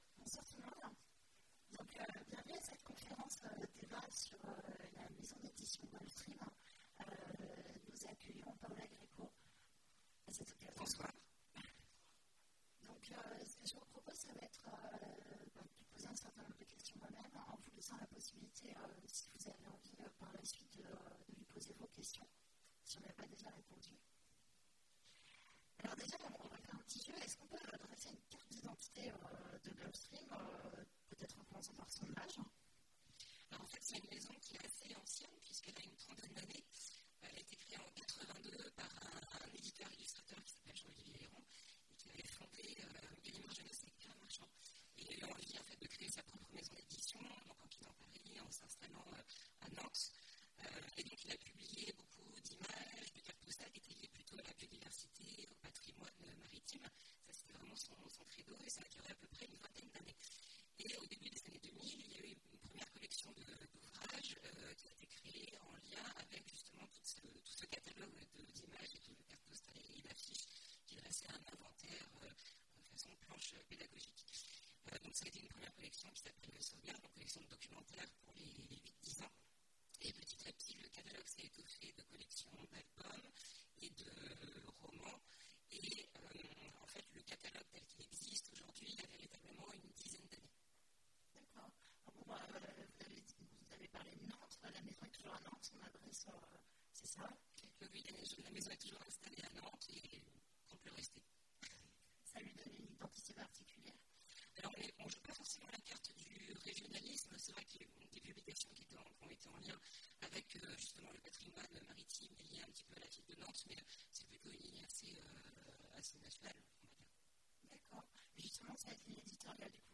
- le 31/10/2017 Partager Commenter Imaginales 2017 : Conférence Gulf Stream, comment ça marche ?
Mots-clés Rencontre avec une maison d'édition Conférence Partager cet article